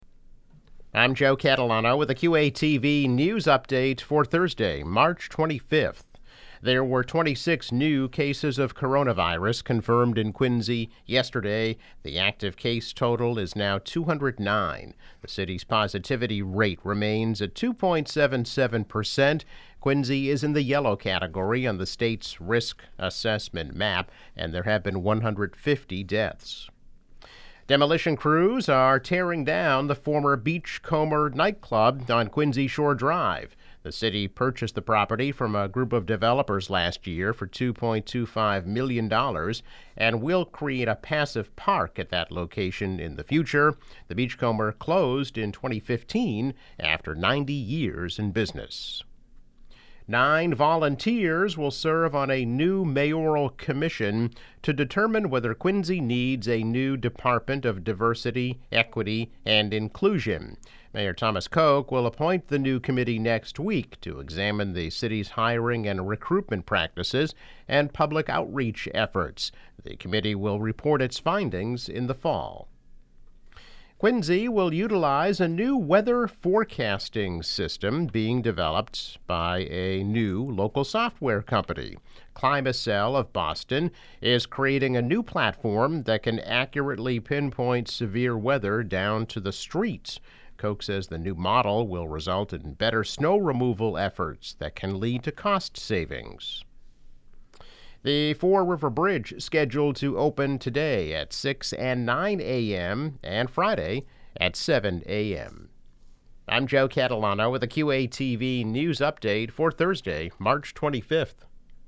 News Update - March 25, 2021